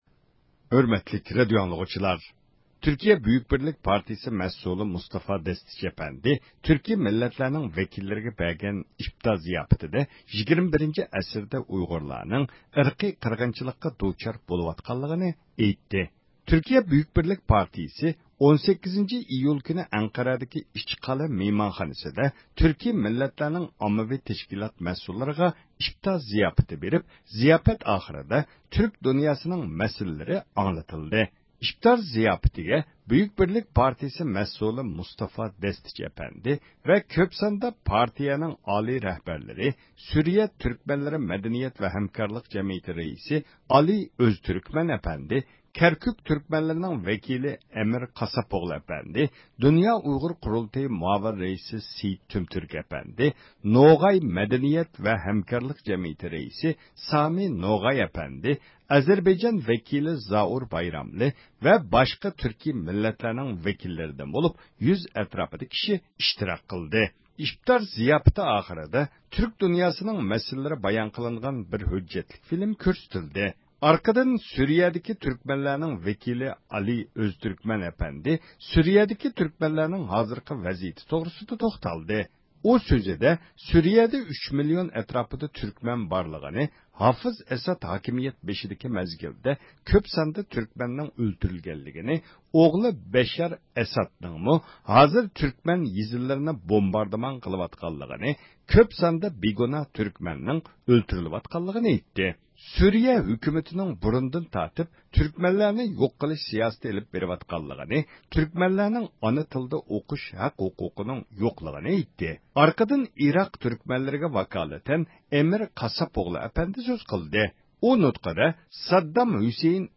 ئەڭ ئاخىرىدا بۇ پائالىيەتنى ئويۇشتۇرغان بۈيۈك بىرلىك پارتىيەسى رەئىسى مۇستافا دەستەچى ئەپەندى سۆز قىلدى.